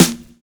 FINE BD    9.wav